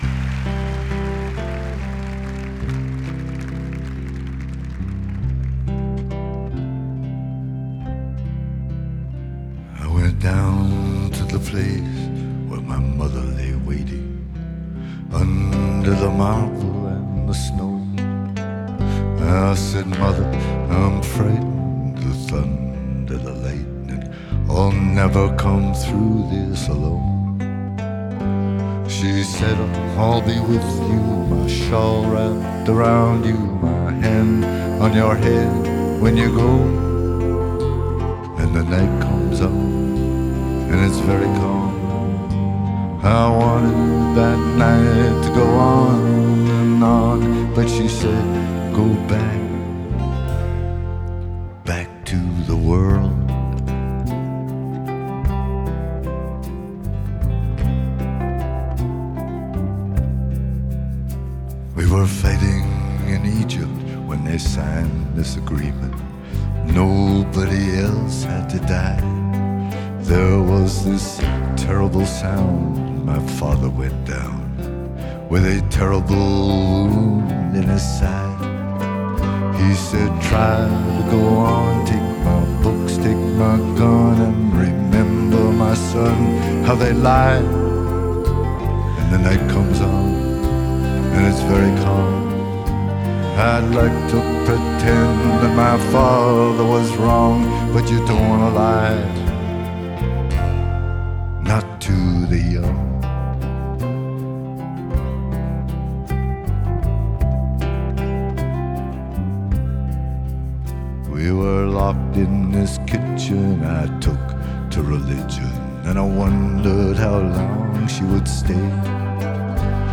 Genre: Folk, Blues, Singer-Songwriter
Live at Mönchengladbach Show, 2012